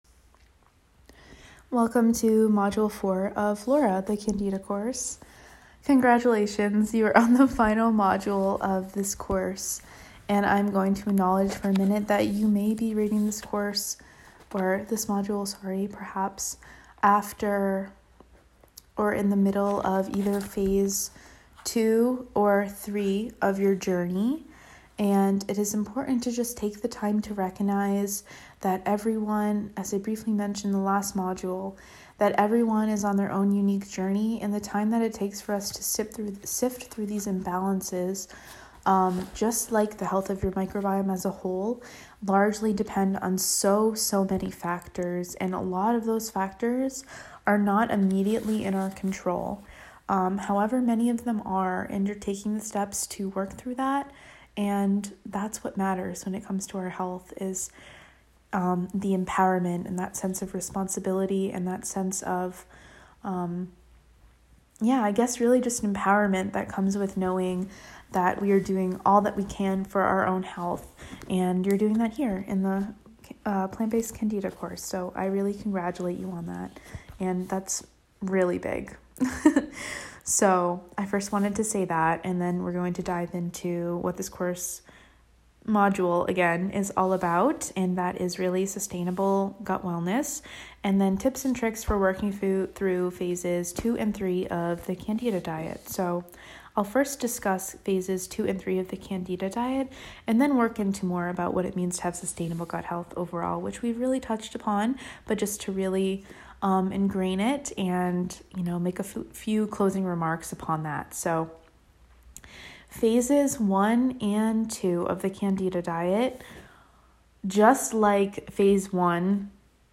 Audio Lesson: Audio Lesson: Follow Your Gut